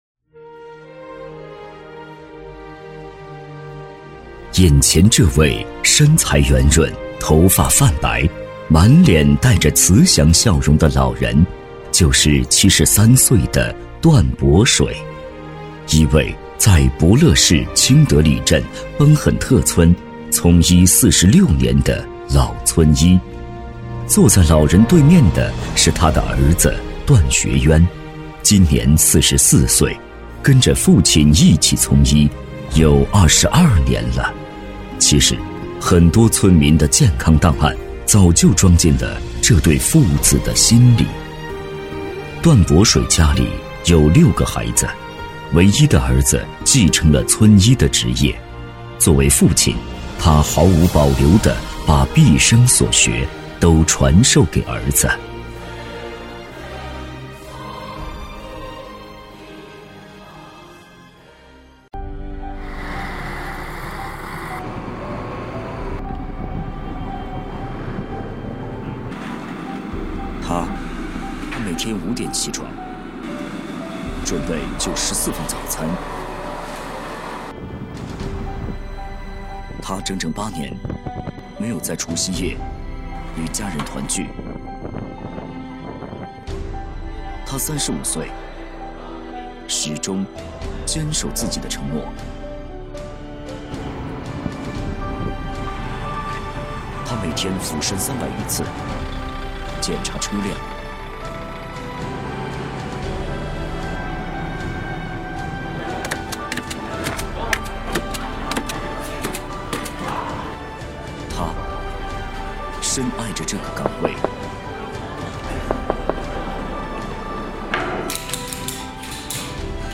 职业配音员全职配音员浑厚大气
• 男S353 国语 男声 专题片-人物专题片-舒缓 大气浑厚磁性|沉稳